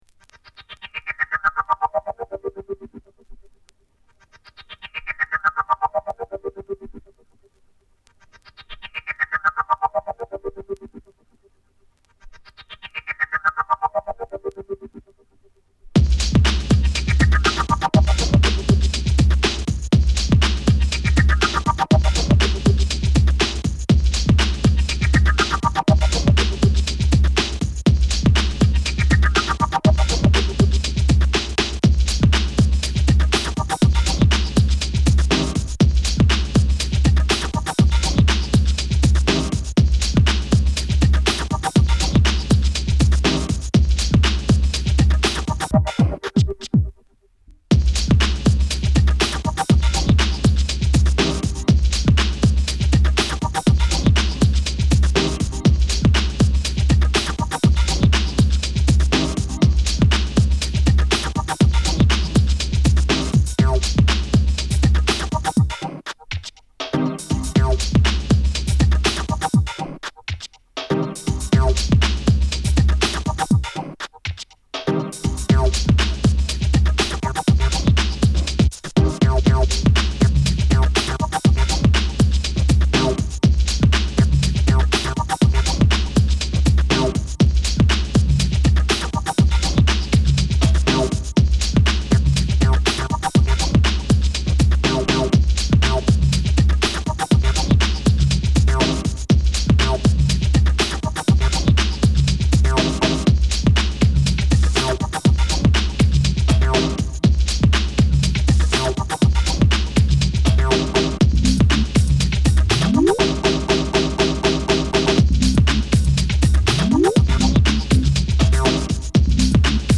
スムースなダブ・テクノA2